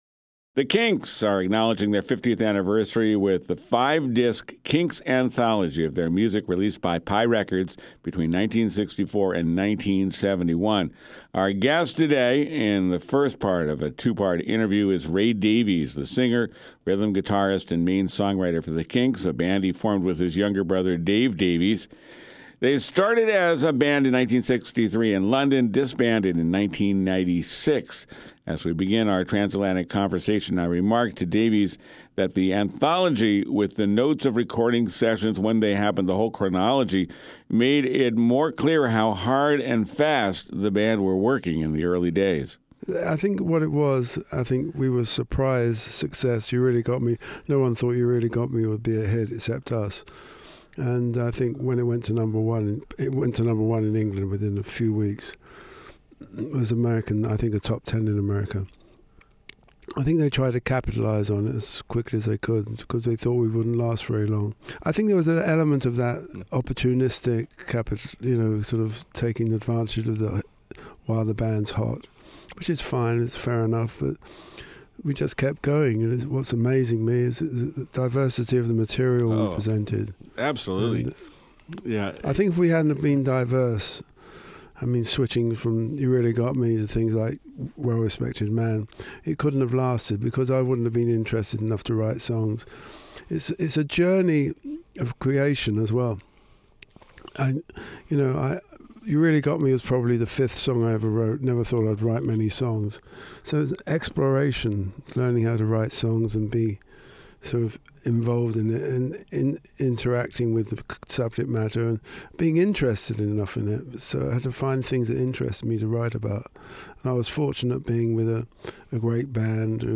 On the occasion of a new Kinks anthology, the British band's singer discusses creative frustration.